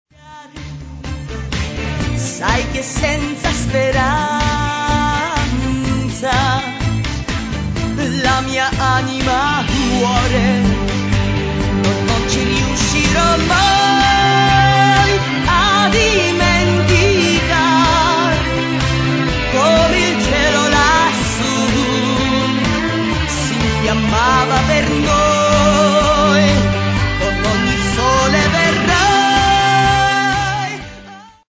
disko verzija pjesme, el. gitare